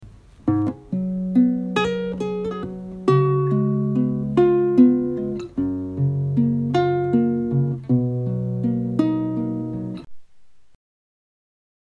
To take you through this process, here is a short idea of a guitar riff that I recorded on my phone a while back.
Guitar riff idea
With the written emphases and re-listening to the recording, I can hear that strong beats are repeated every 6 beats.
For the guitar riff idea, I heard Gb as the home key, so I wrote it again using the Gb key signature.
Guitar-riff-idea.mp3